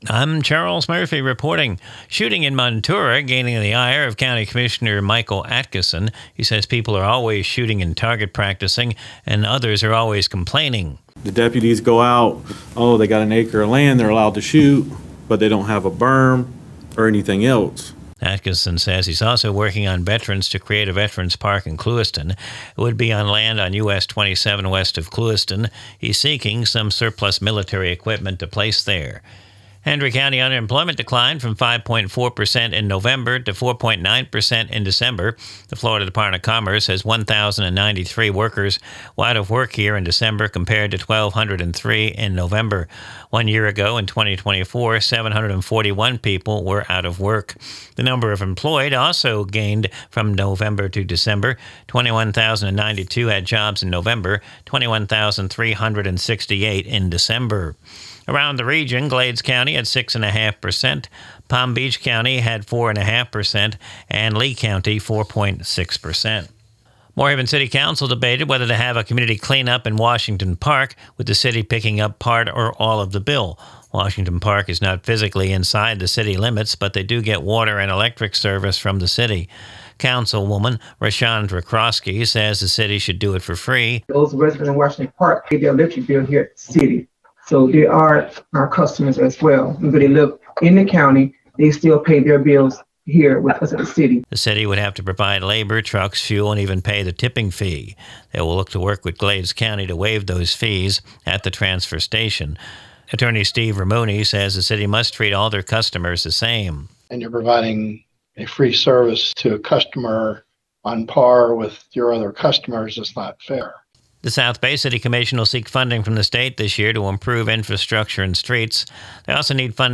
NEWS
Recorded from the WAFC daily newscast (Glades Media).